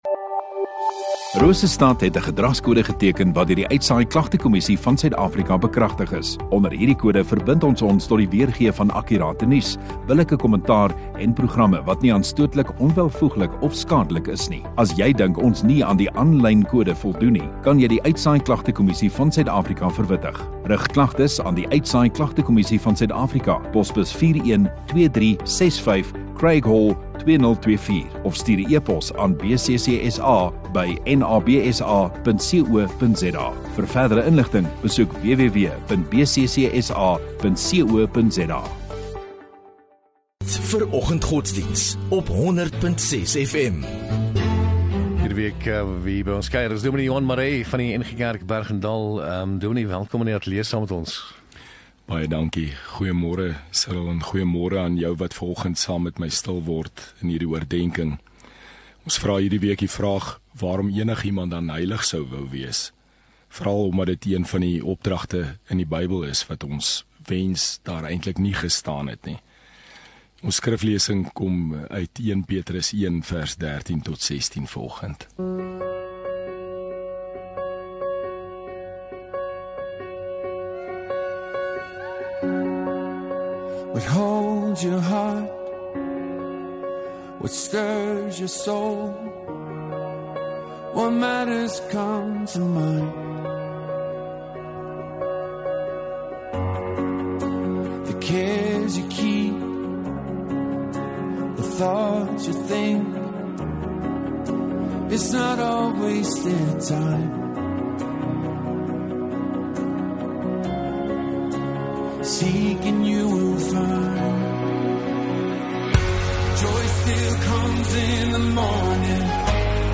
9 Jul Dinsdag Oggenddiens